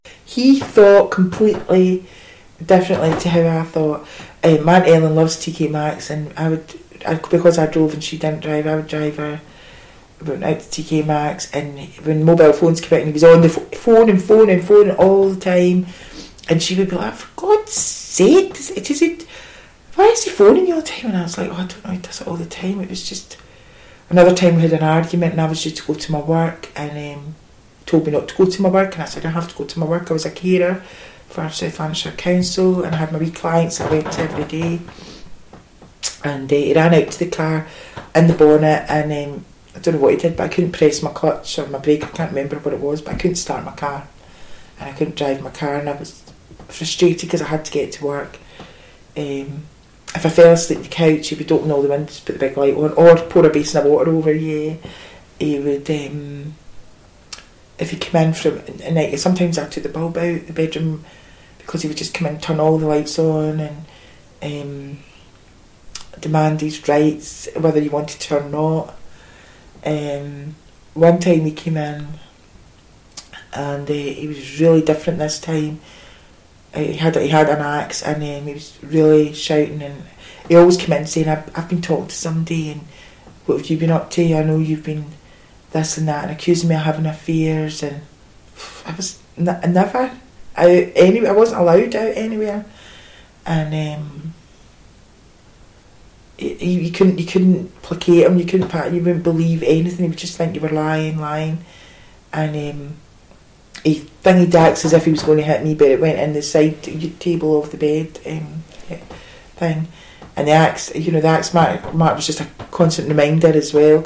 The film and audio oral history interview excerpts below are used as primary source material throughout the lessons in the Speaking Out young people’s learning resource.